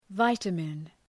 Προφορά
{‘vaıtəmın}
vitamin.mp3